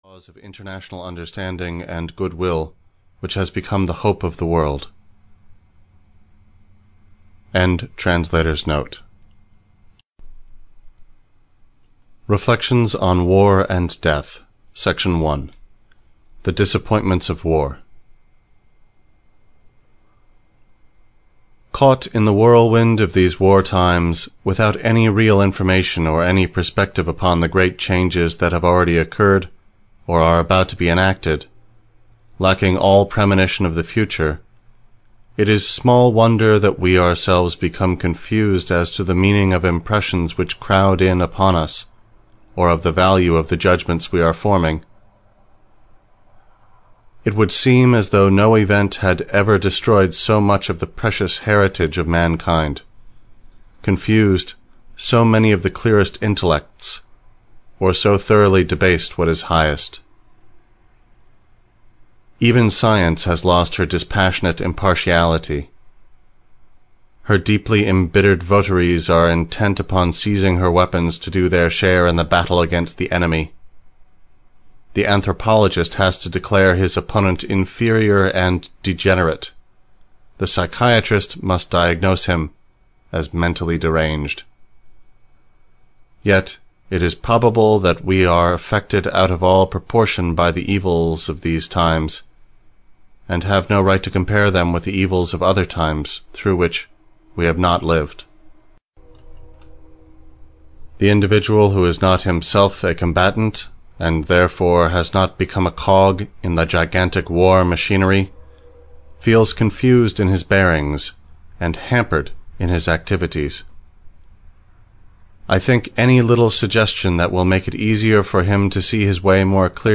Audio knihaReflections of War and Death (EN)
Ukázka z knihy